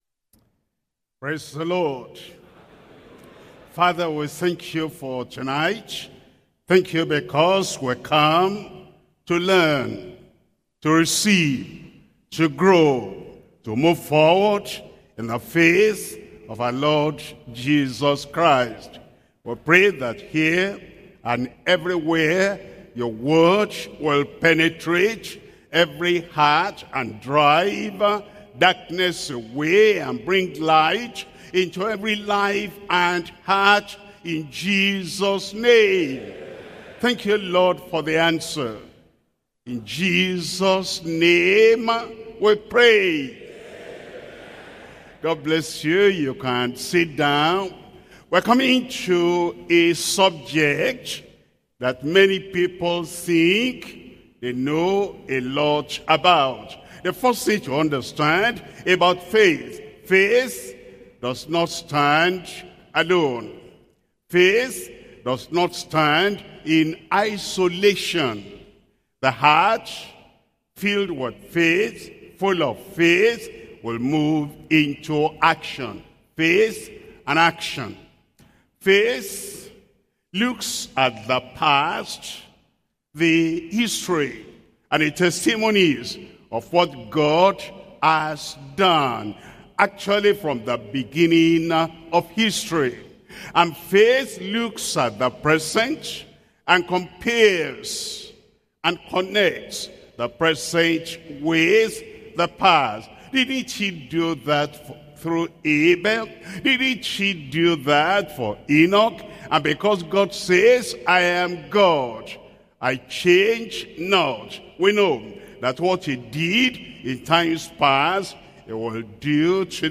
Sermons - Deeper Christian Life Ministry
2025 Workers Training